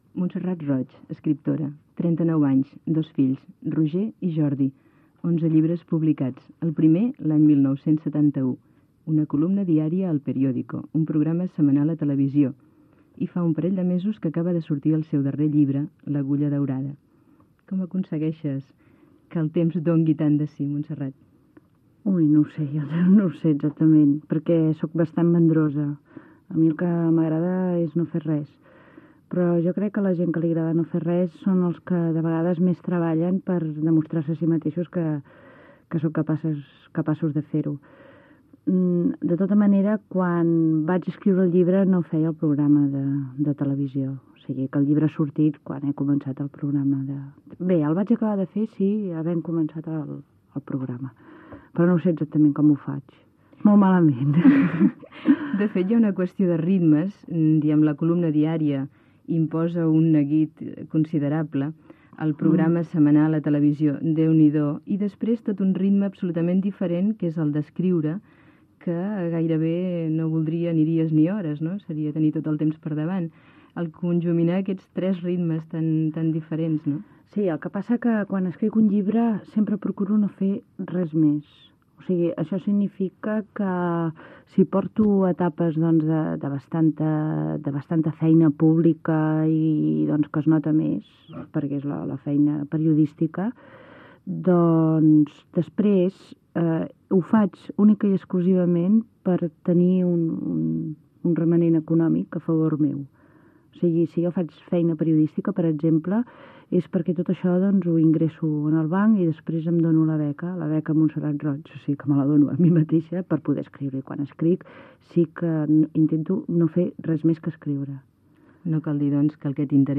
140165fa392e1f7c81f25e977c64307cbaea5dba.mp3 Títol Ràdio 4 Emissora Ràdio 4 Cadena RNE Titularitat Pública estatal Nom programa Radioactivitat Descripció Entrevista a l'escriptora Montserrat Roig que ha publicat el llibre "L'agulla daurada". S'hi parla se la seva vida i obra literària